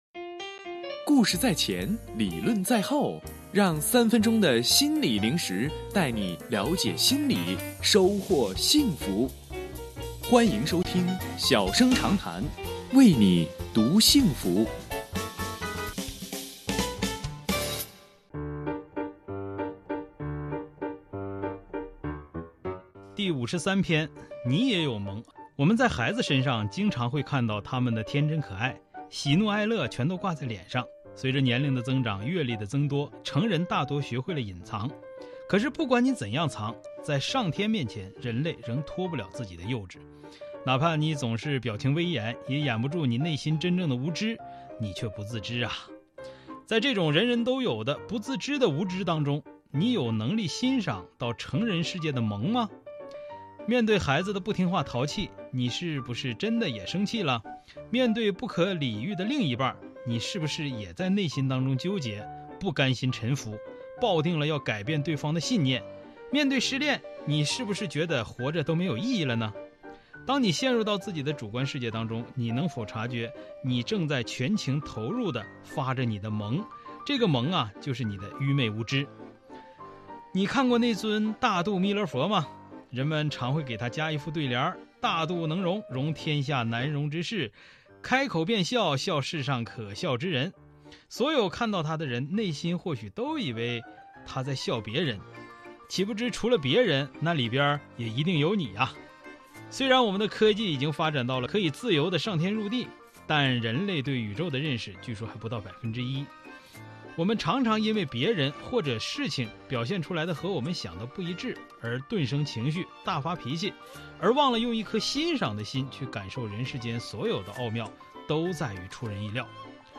音频来源：吉林广播电视台 新闻综合广播